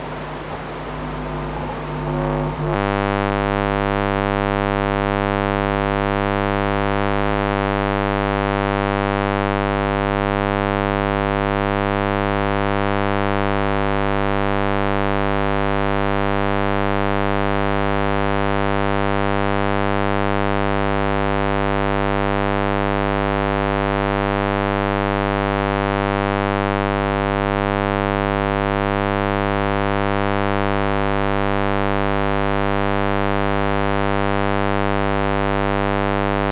Here is another recording but now recorded with an induction loop receiver to record the electromagnetic modulation of the motor by my thinking (jack and jill went up the hill……)
The motor was outside the home.
REC175-induction-loop-receiver.mp3